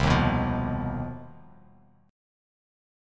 CmM7bb5 Chord